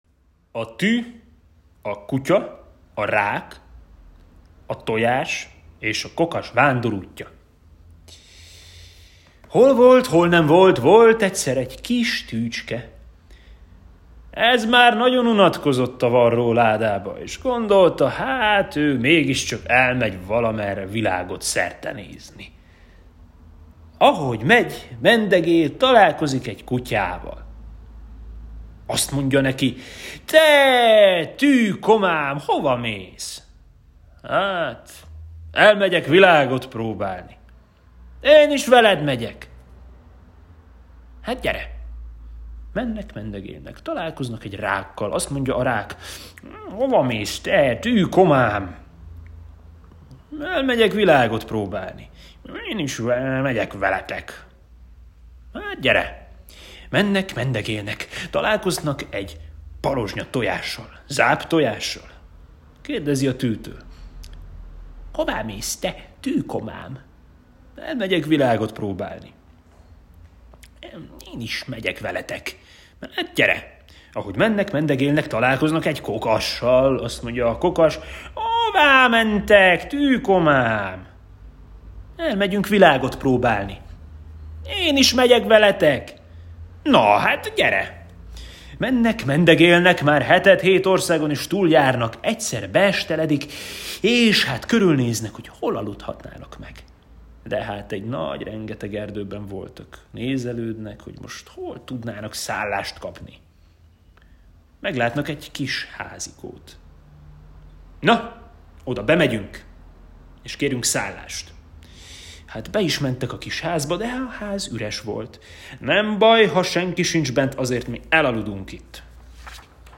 Hangos mesék